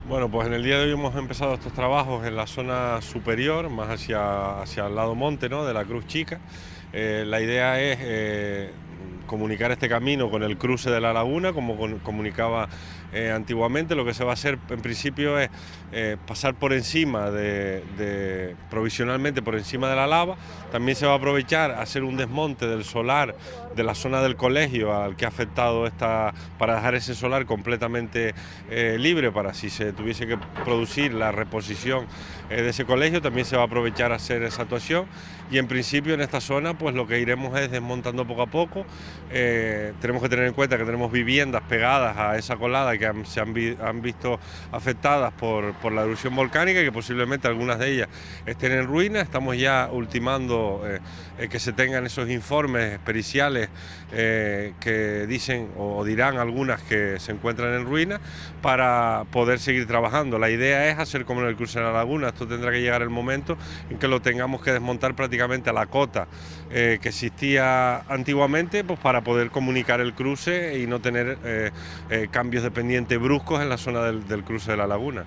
Declaraciones Borja Perdomo_0.mp3